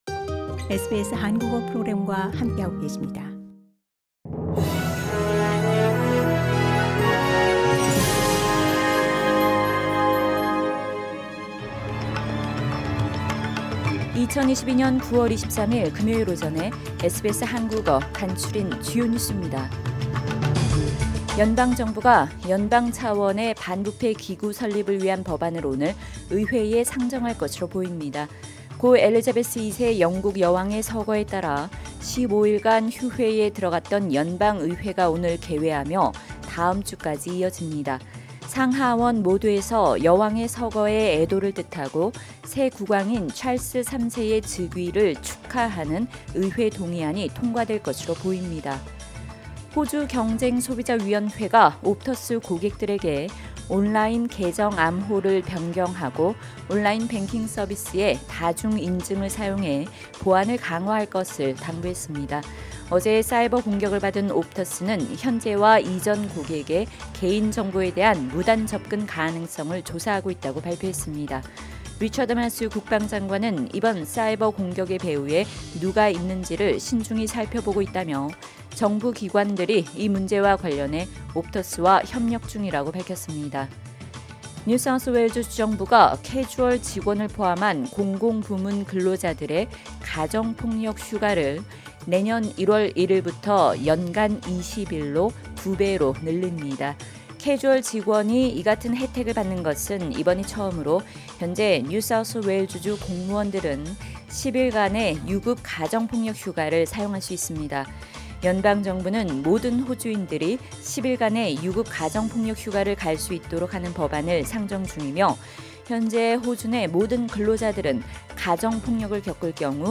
SBS 한국어 아침 뉴스: 2022년 9월 23일 금요일